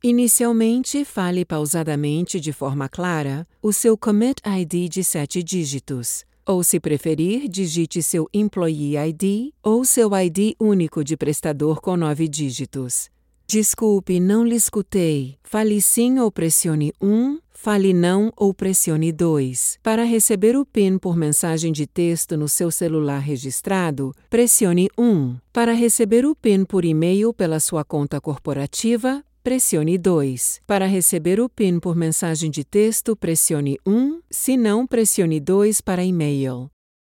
Mature Adult, Adult, Young Adult
Has Own Studio
Portuguese
phone message